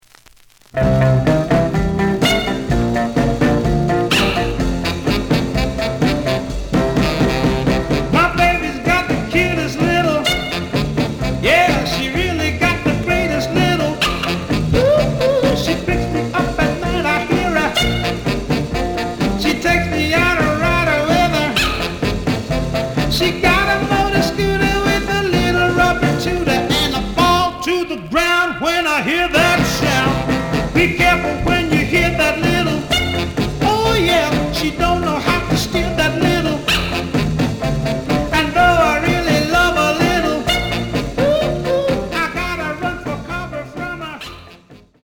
試聴は実際のレコードから録音しています。
●Genre: Rhythm And Blues / Rock 'n' Roll